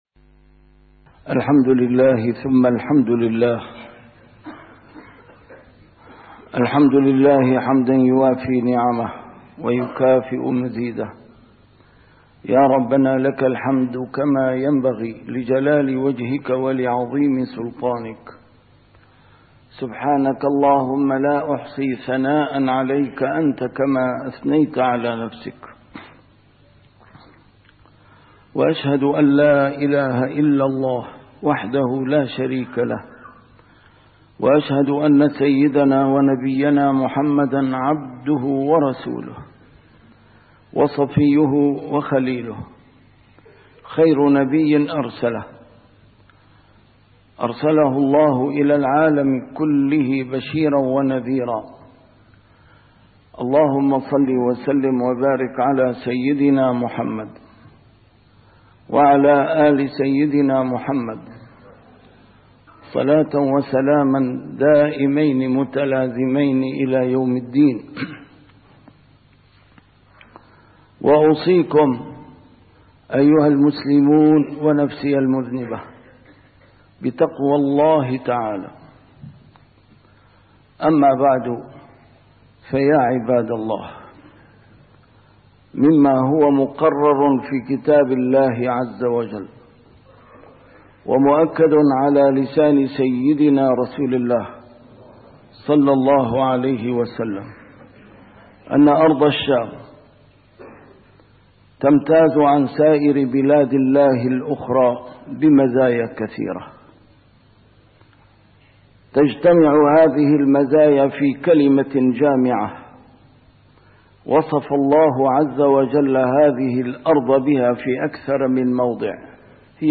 A MARTYR SCHOLAR: IMAM MUHAMMAD SAEED RAMADAN AL-BOUTI - الخطب - السور الذي يحمي الشام من الأوغاد